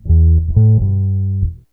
BASS 36.wav